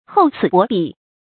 注音：ㄏㄡˋ ㄘㄧˇ ㄅㄛˊ ㄅㄧˇ
厚此薄顧的讀法